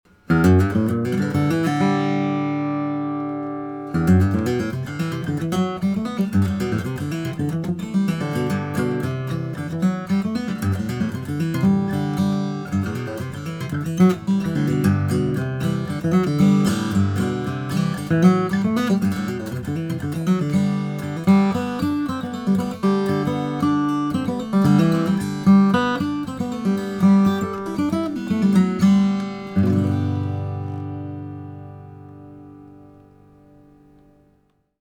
Newest 20 Songs flatpicked guitar songs which Flatpicker Hangout members have uploaded to the website.